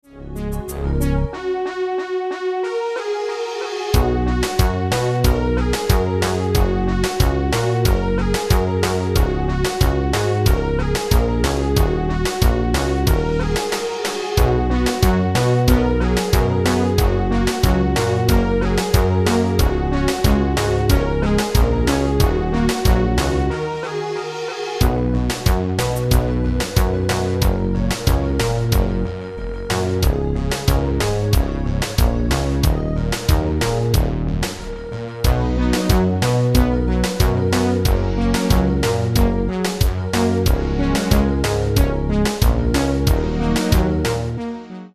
Hip-Hop and Rap Midi File Backing Tracks.